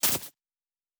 pgs/Assets/Audio/Sci-Fi Sounds/Electric/Spark 14.wav at master
Spark 14.wav